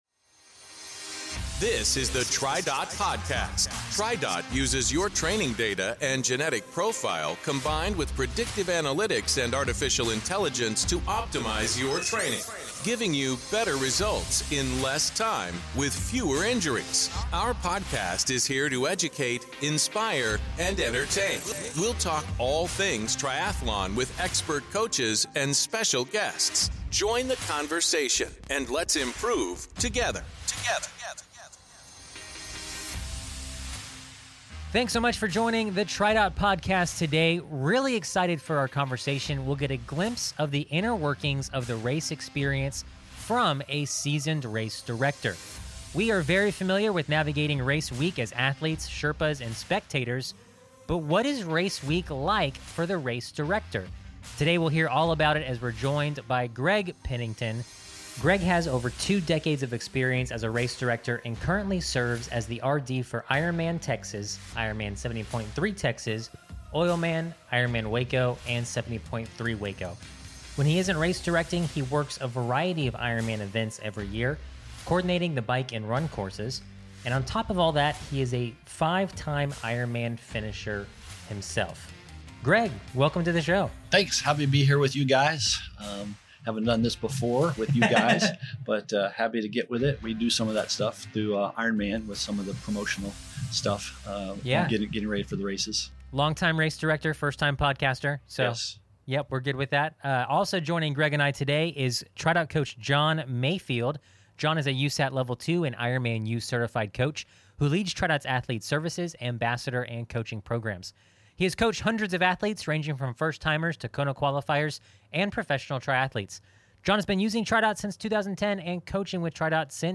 The TriDot Triathlon Podcast